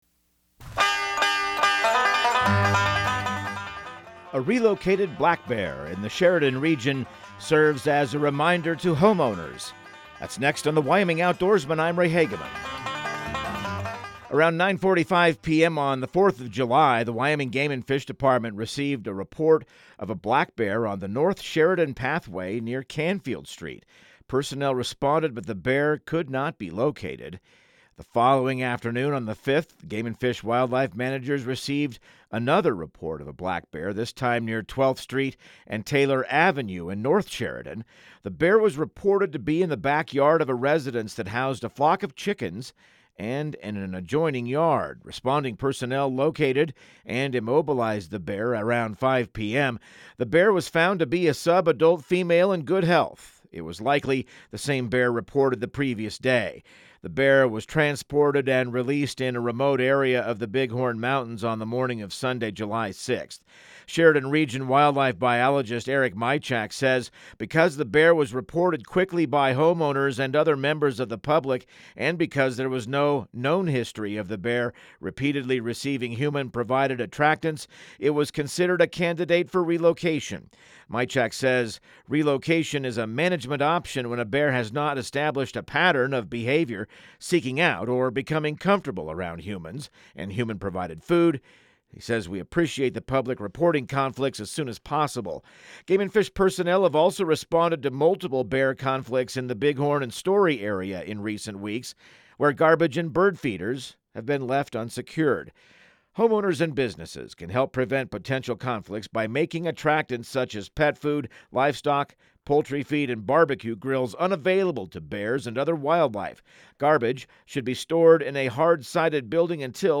Radio news | Week of July 21